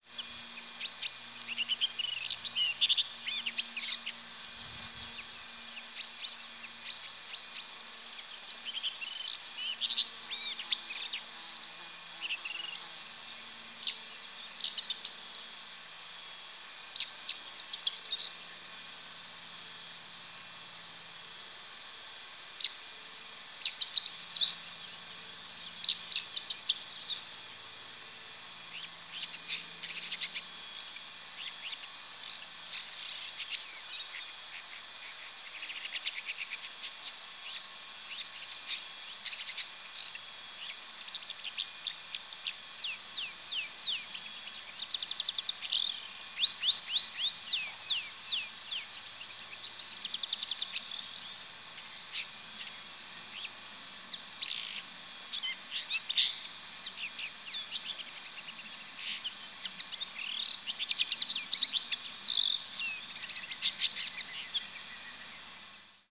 Seine Imitationen von anderen Vogelstimmen, die er in seinen Gesang einbaut, ist unerrreicht.
Sein Gesang klingt gepresst, mit knischenden Lauten. Neuntöter rufen häufig heiser ein "dschäää".
neuntoeter-1m.mp3